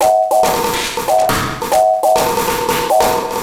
E Kit 03.wav